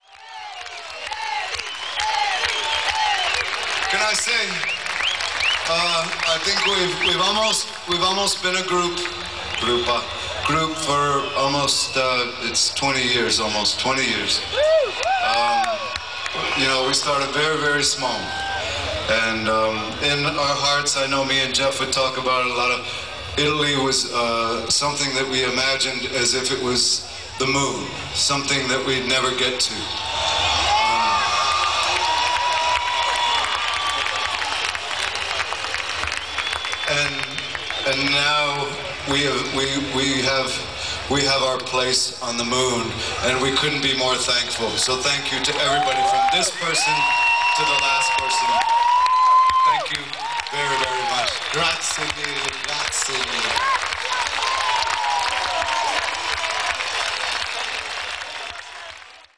Siamo quasi 40.000 persone pronte ad essere trasportate nell’universo Pearl Jam…
E allora, con il suo immancabile fogliettino, ci parla in italiano per renderci parte dei suoi pensieri e delle sue sensazioni a proposito del pezzo scritto a Roma a bordo in una macchina piccolissima nel traffico della capitale mentre viaggia per la città  con alcuni amici, oppure parlando delle varie forme e sfaccettature dell’amore, e poi in inglese comunicando sempre con noi e concludendo il concerto così, a proposito dell’Italia…
EddieVedder.mp3